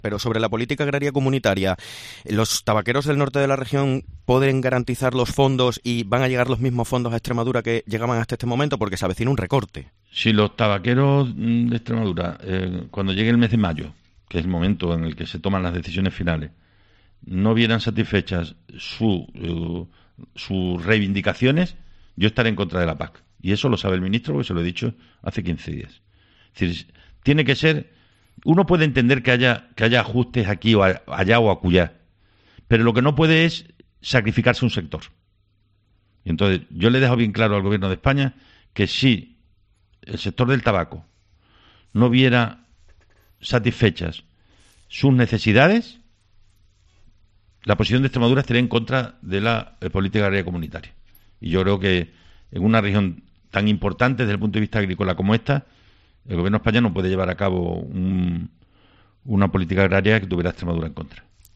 Entrevista al pte de la Junta de Extremadura, Guillermo Fernández Vara en la cadena COPE